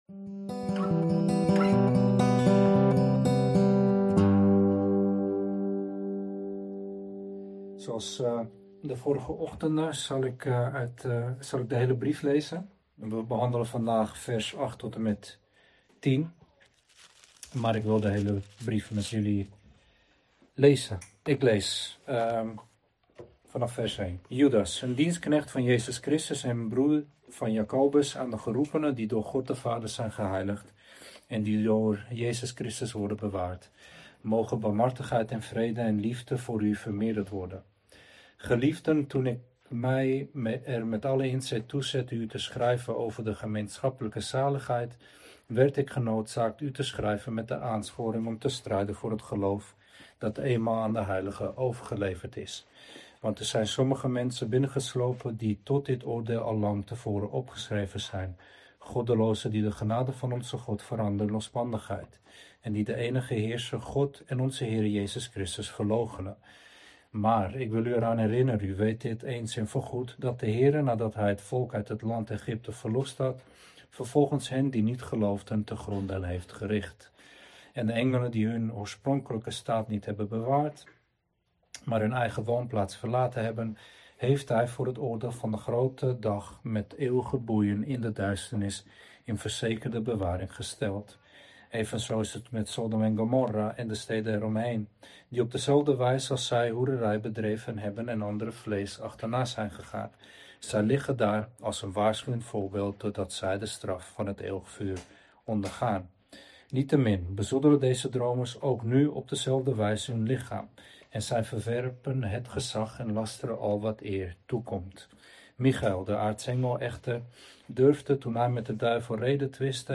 Preek over Judas 1:8-10 | Bijbeluitleg
Heldere Bijbeluitleg en praktische toepassing vanuit Gods Woord. Verklarende prediking.